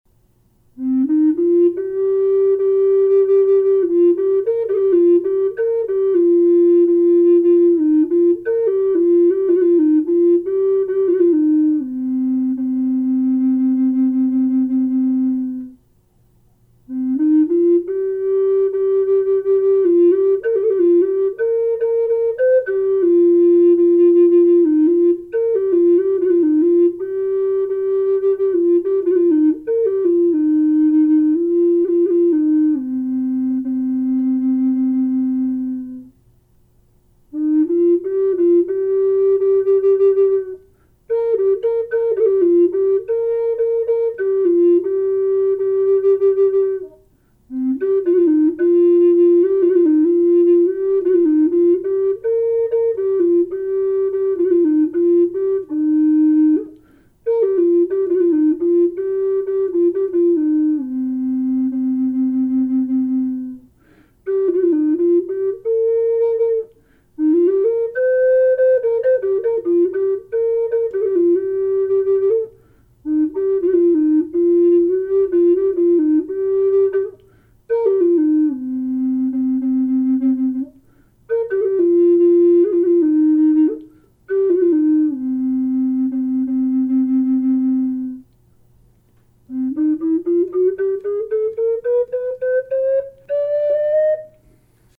Beautiful Western Red Cedar Great Horned Owl carved Flute in Low Cm.
Gorgeous voice & tone.
low-c-horned-owl-1.mp3